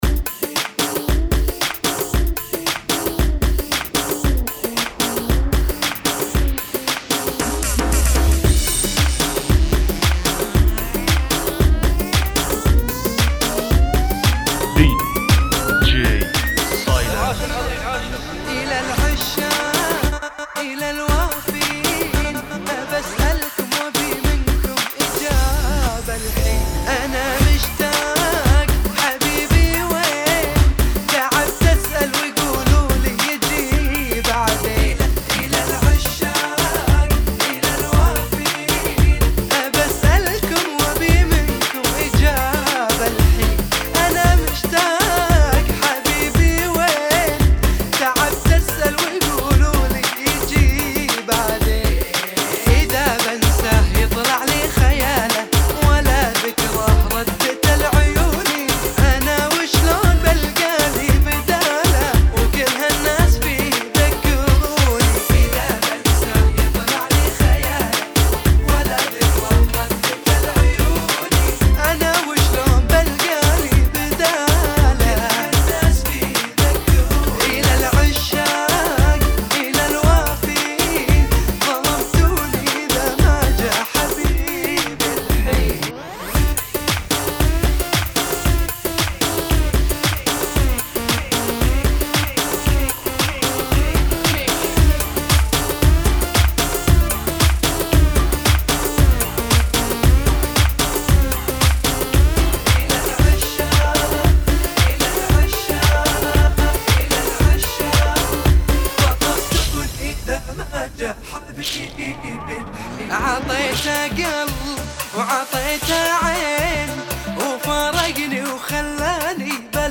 [ Bpm 114 ]